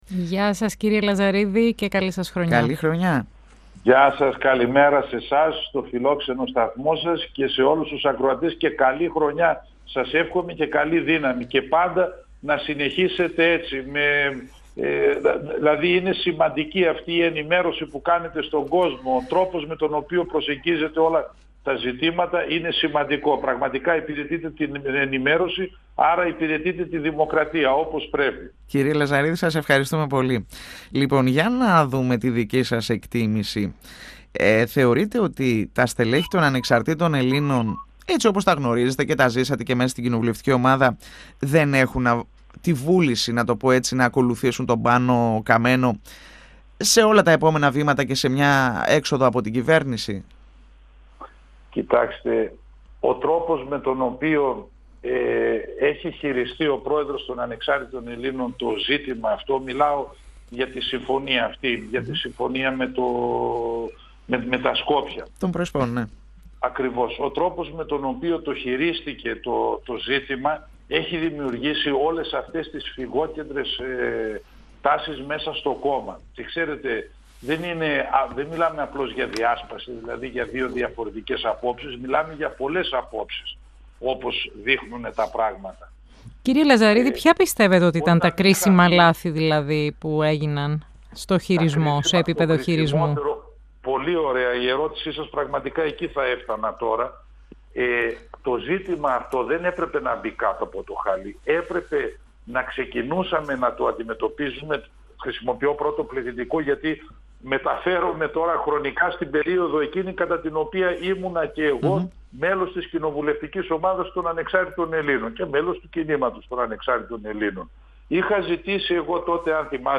Τη βεβαιότητα ότι η κυβέρνηση θα βρει τους 151 βουλευτές προκειμένου να περάσει από τη Βουλή η συμφωνία των Πρεσπών εξέφρασε, μιλώντας στον 102 fm της ΕΡΤ3, ο ανεξάρτητος βουλευτής Β΄ Θεσσαλονίκης Γιώργος Λαζαρίδης.